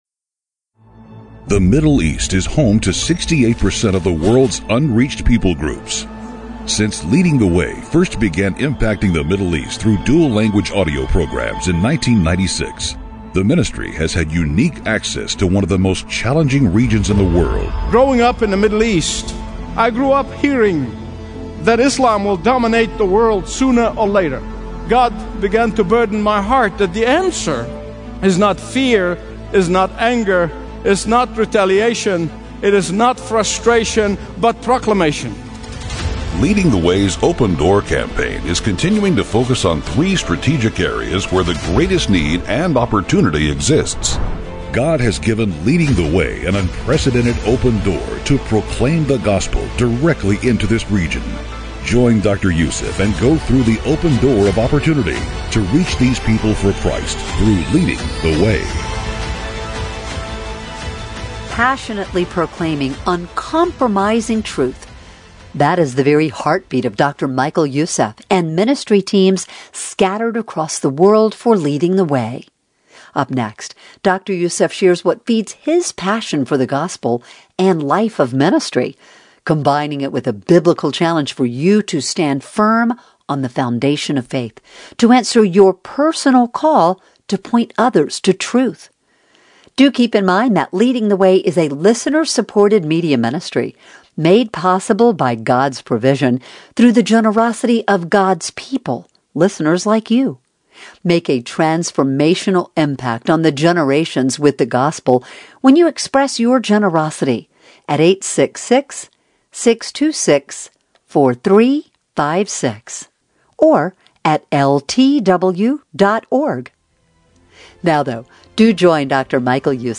Stream Expository Bible Teaching & Understand the Bible Like Never Before.